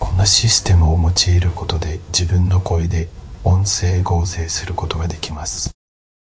Coqui TTSを用いたボイスクローニング
合成の質感は流暢とまではいきませんが、十分使えるレベルです。
[ 日本語合成例 ]